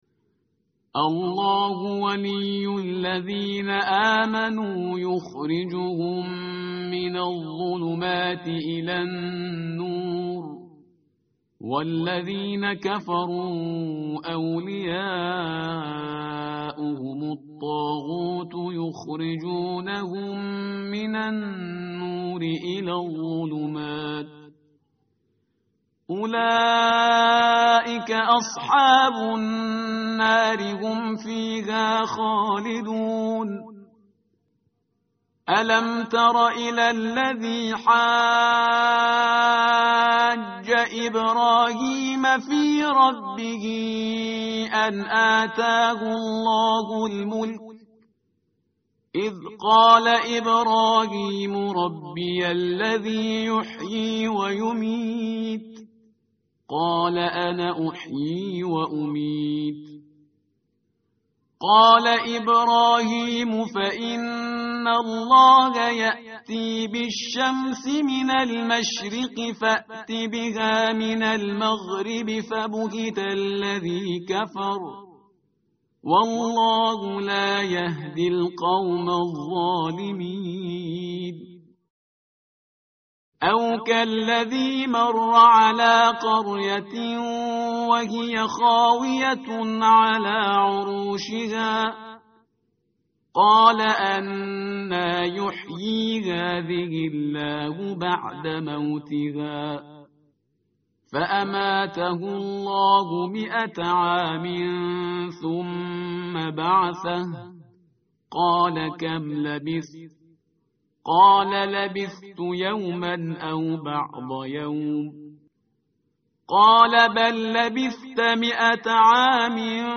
tartil_parhizgar_page_043.mp3